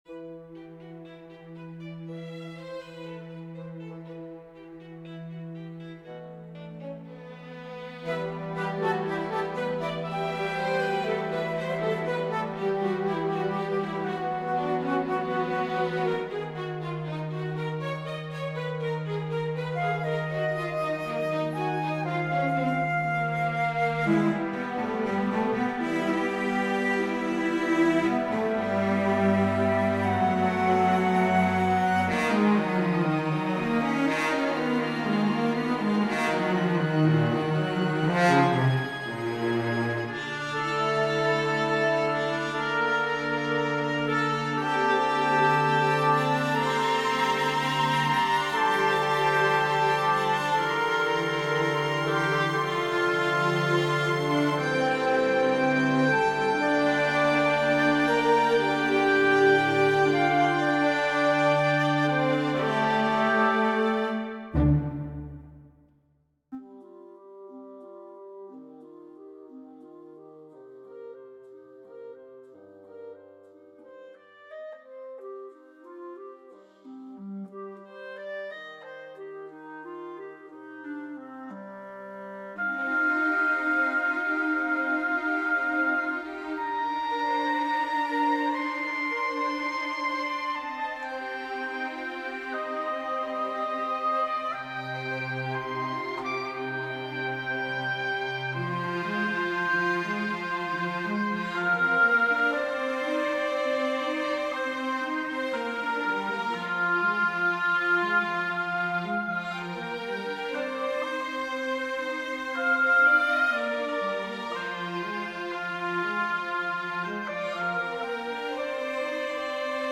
Haggis Steeped In Whiskey for chamber orchestra